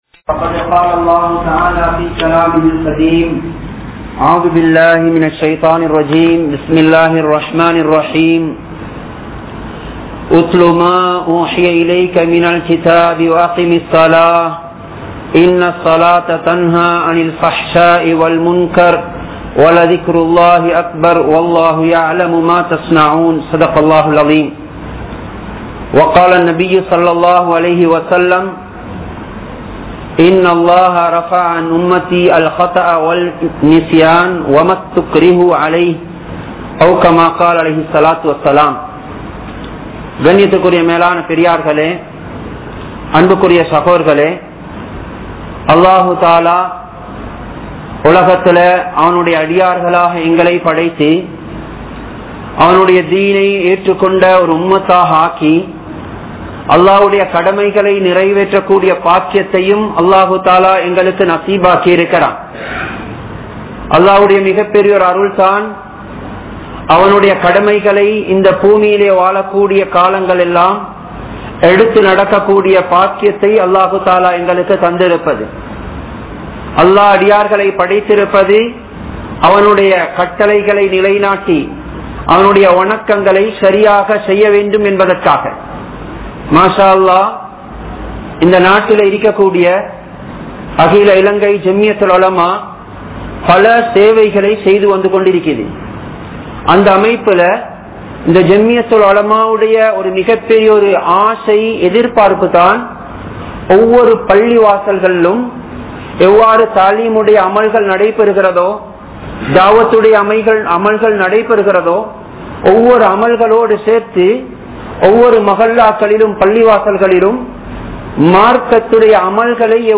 Tholuhaiel Marathi Eatpattaal (தொழுகையில் மறதி ஏற்பட்டால்) | Audio Bayans | All Ceylon Muslim Youth Community | Addalaichenai